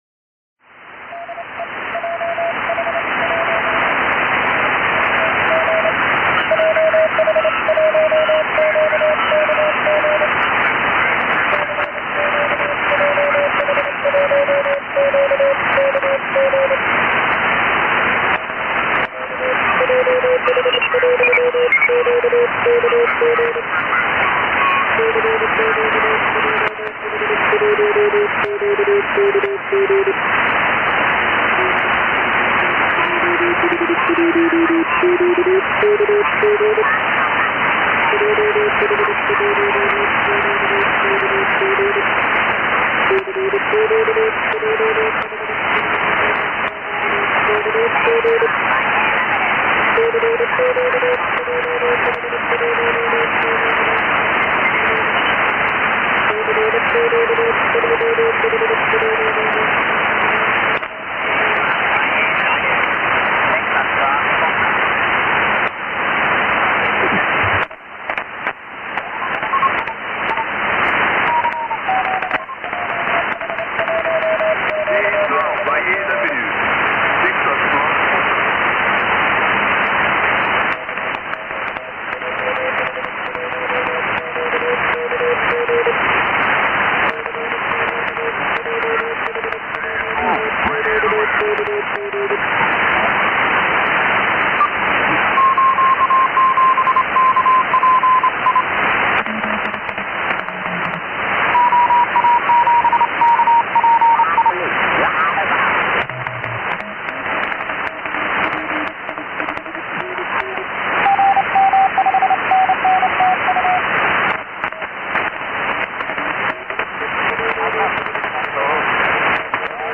北東の空に現れた NEXUS 衛星は久しぶりの Mode-J。145.91 付近でアップリンクすると、435.90 付近でしっかりダウンリンクが取れた。しばらくＣＱを出すと、７管内の局に呼ばれてファーストＱＳＯ。